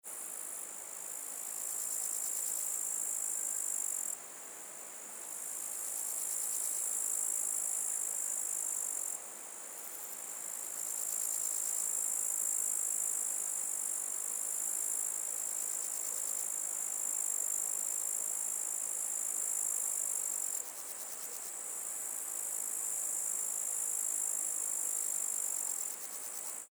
Звуки травы
Звук кузнечиков стрекочущих в траве